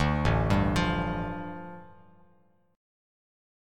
A7sus4#5 chord